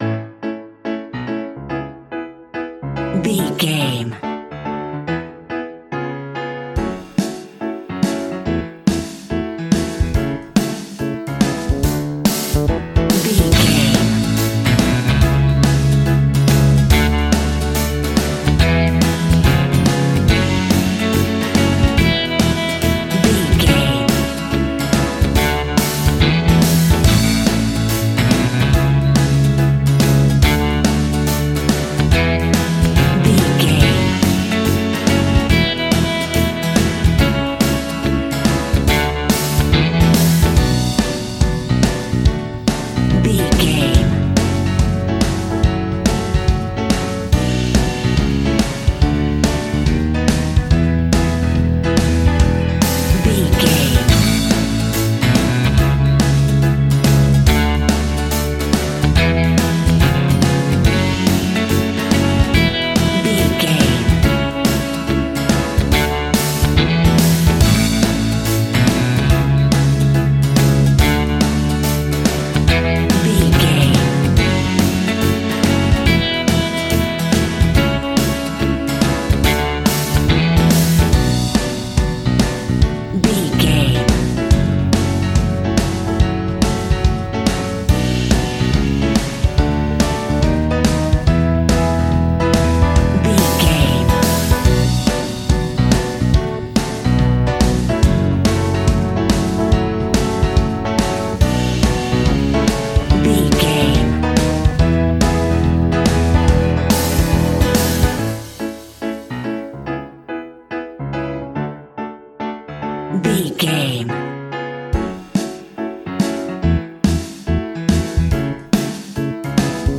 Uplifting
Ionian/Major
pop rock
indie pop
fun
energetic
acoustic guitars
drums
bass guitar
electric guitar
piano
electric piano
organ